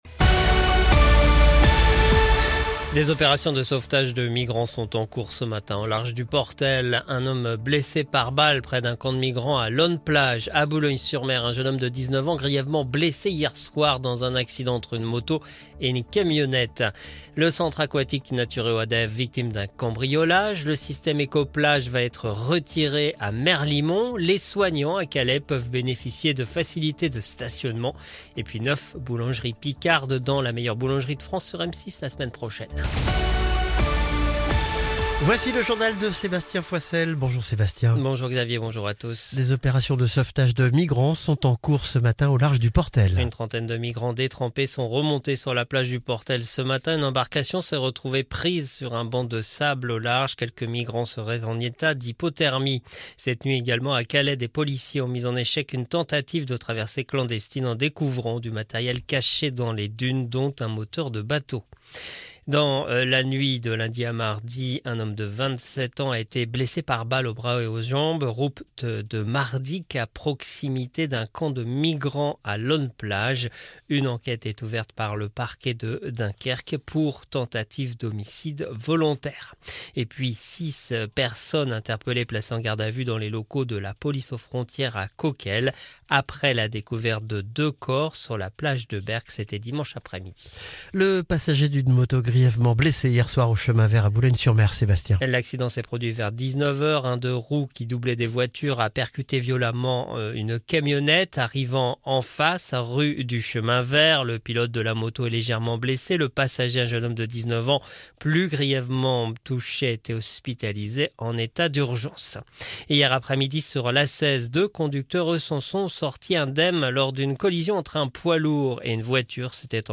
Le journal du mercredi 12 février 2025